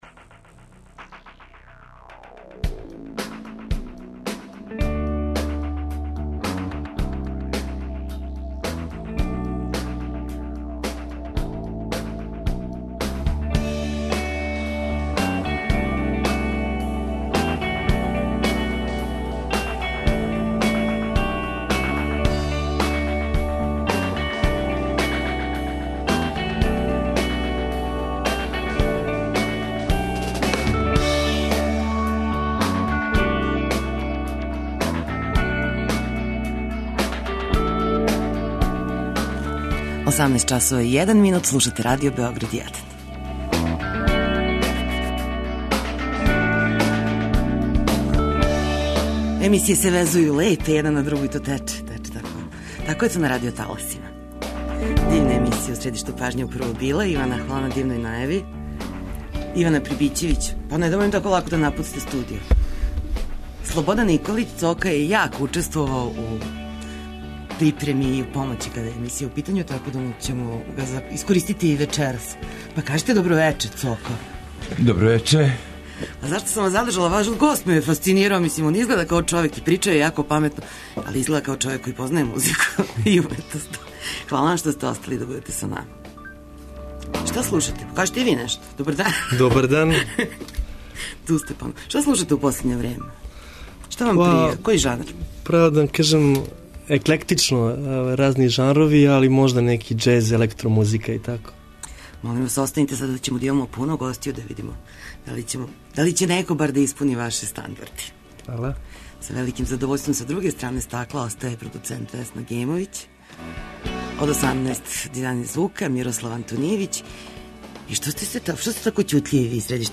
Гост емисије биће Срђан Гојковић Гиле, поводом концерта који ће Електрични оргазам одржати у петак, у београдском Дому омладине, а повод је промоција албума 'То што видиш то и јесте'.